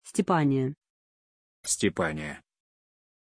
Pronunciation of Stéphanie
pronunciation-stéphanie-ru.mp3